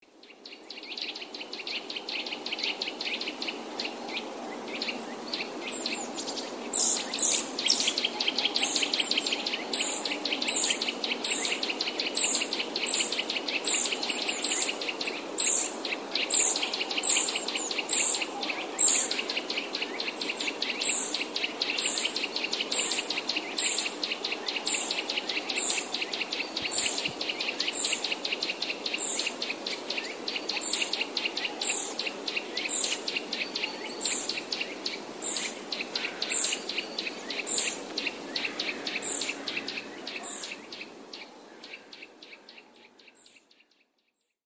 Kowalik - Sitta europaeus
Kowalik potrafi też odzywać się
bardzo wysokim, wibrującym głosem. Głos ten słychać na tle szczebiotania innego kowalika.